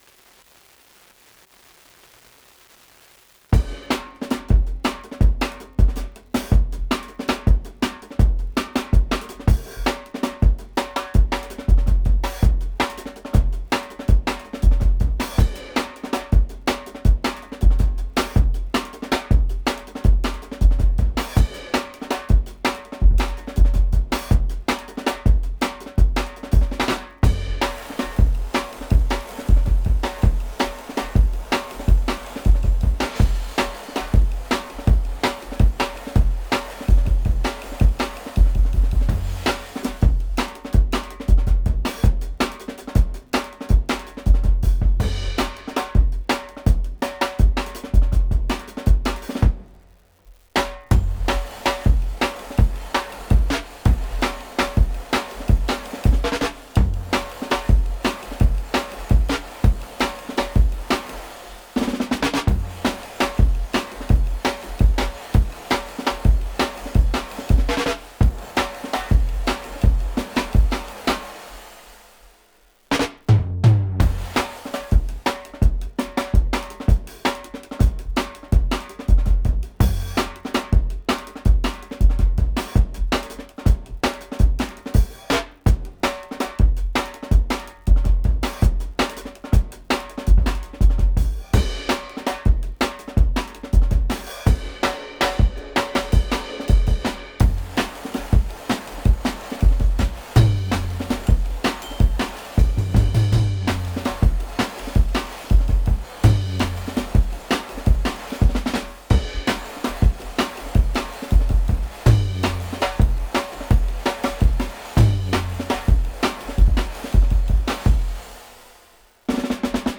DRUM n BASS BEATS.wav